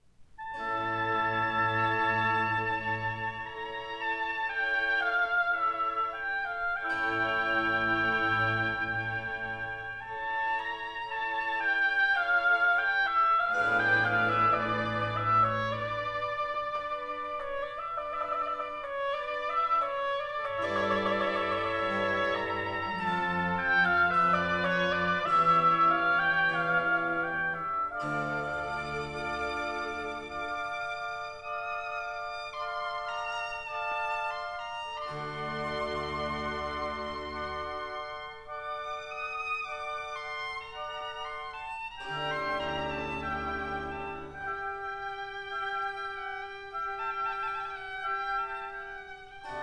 one instrument to a part